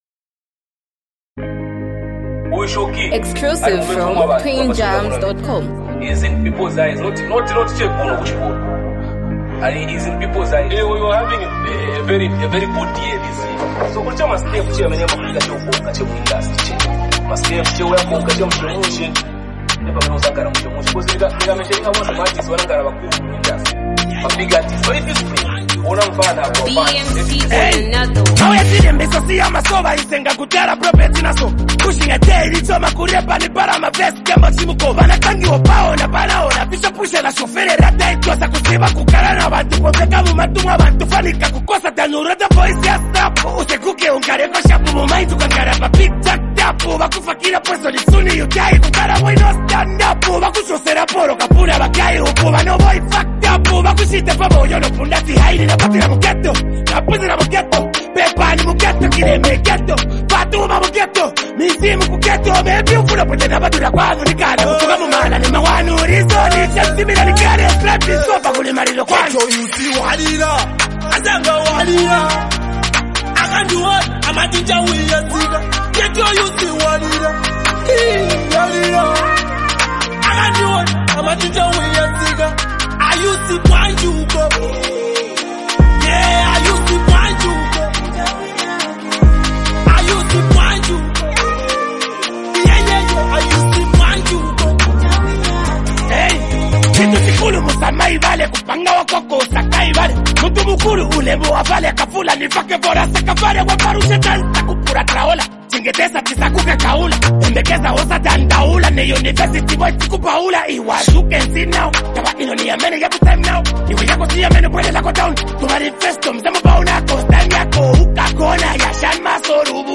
a powerful and emotional song
a motivational anthem